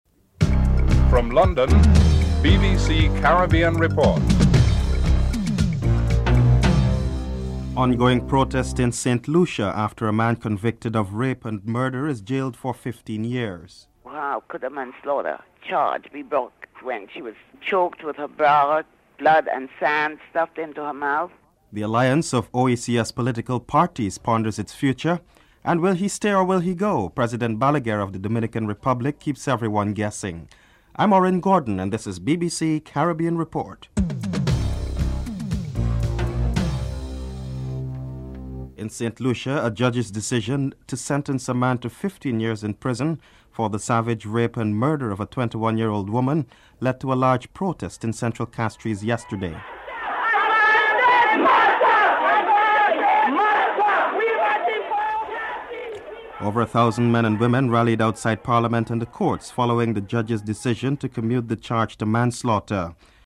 The British Broadcasting Corporation
1. Headlines (00:00-00:38)
3. The alliance of OECS political parties ponders its future. SCOPE spokesman Dr Ralph Gonsalves is interviewed (05:44-07:42)